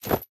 Equip_chain1.ogg.mp3